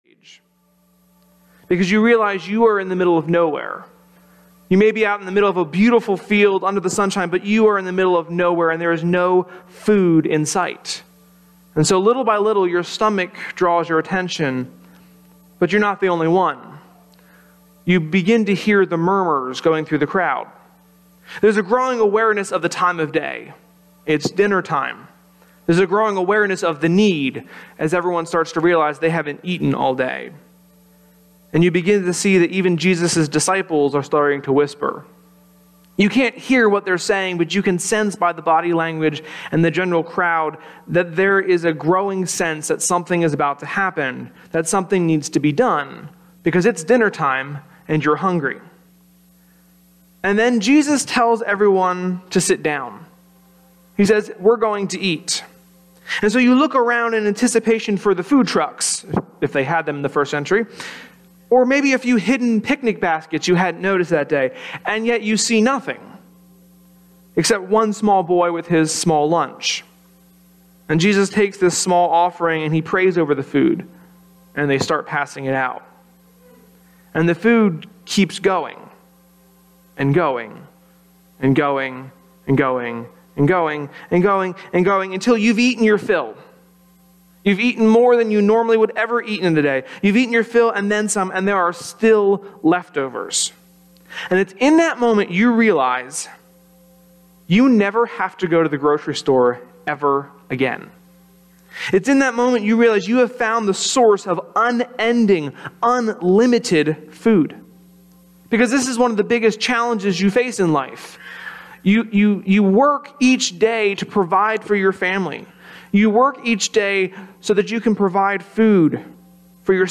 (Our apologizes…the first two minutes of the sermon did not record.